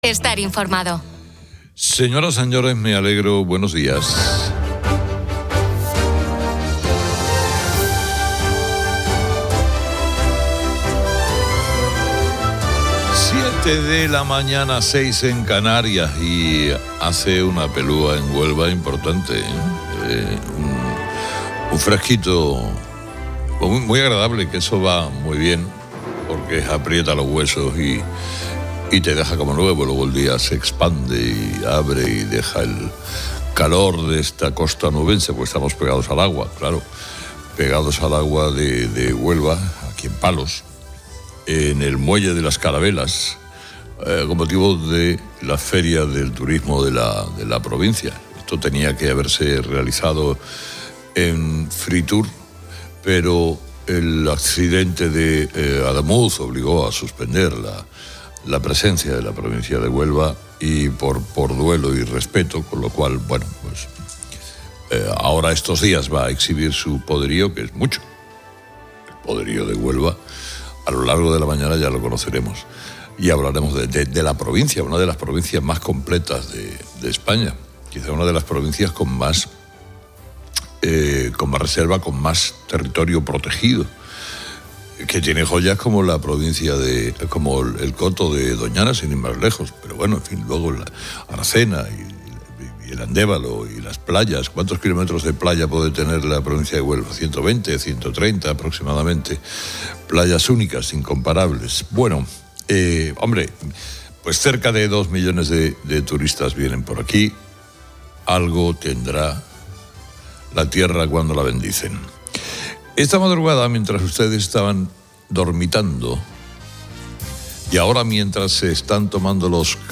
El programa se emite desde Huelva, en la feria de turismo de la provincia, destacando sus atractivos naturales, como el Coto de Doñana y sus playas,...